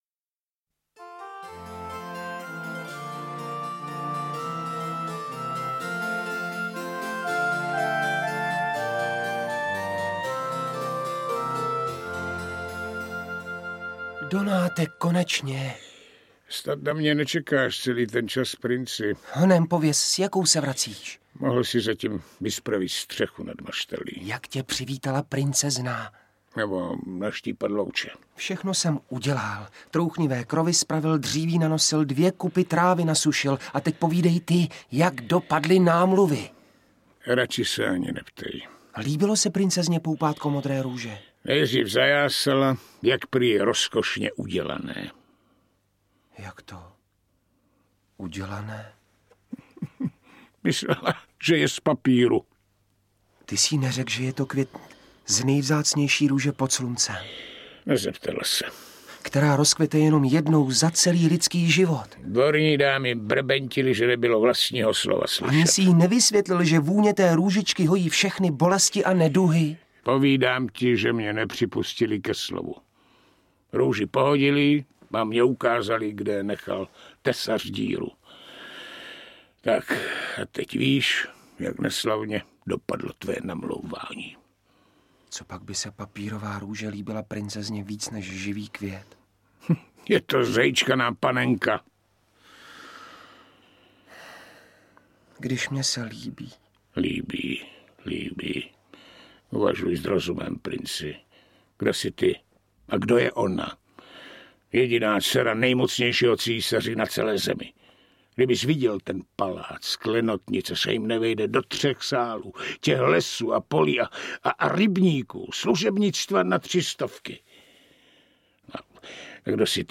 Interpreti:  Aňa Geislerová, Uršula Kluková, Jiří Langmajer, Sabina Laurinová, František Němec, Libuše Šafránková, Alena Vránová